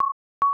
The beating sound caused by the mixing of the 1090 Hz and 1121 Hz frequencies results in a complex tone that is less desirable than the 1121 Hz frequency alone.
The synthetic sound of the 1090 Hz and 1121 Hz mixture is compared to a synthetic sound of just the 1121 Hz frequency in the wave trace illustration below.